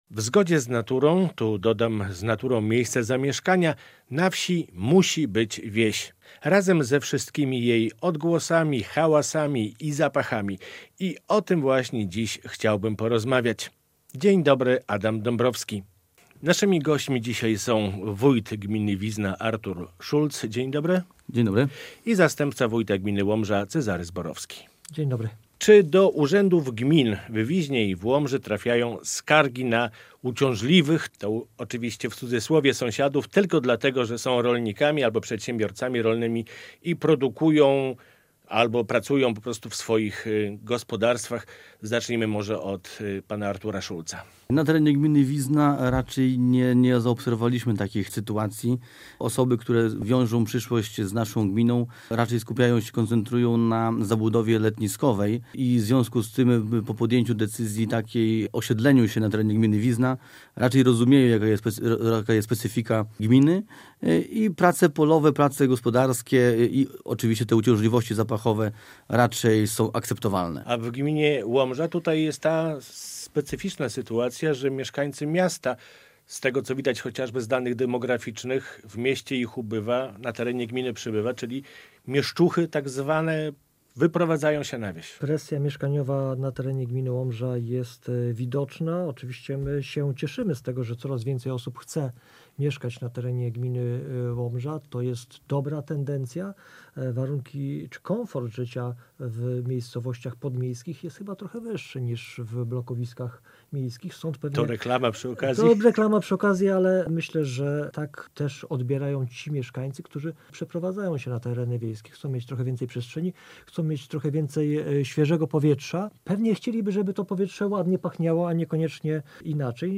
O "planowaniu wsi" i jego konsekwencjach z wójtem gminy Wizna Arturem Szulcem i zastępcą wójta gminy Łomża Cezarym Zborowskim rozmawia